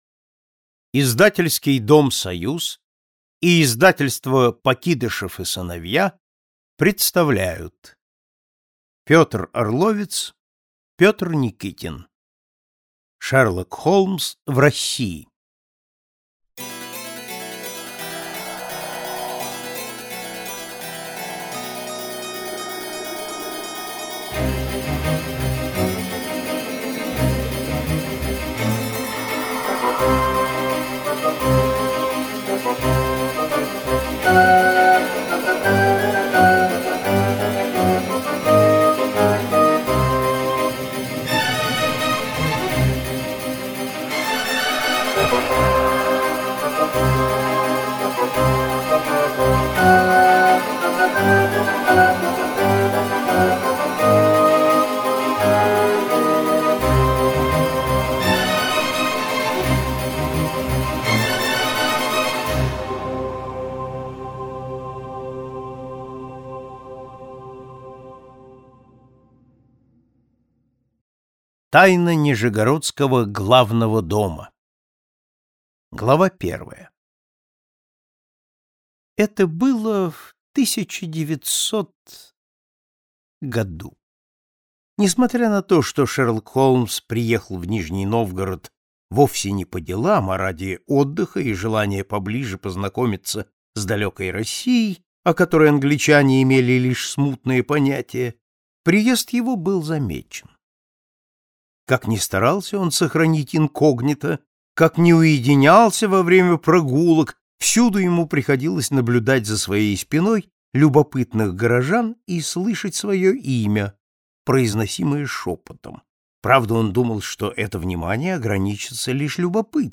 Аудиокнига Шерлок Холмс в России | Библиотека аудиокниг
Прослушать и бесплатно скачать фрагмент аудиокниги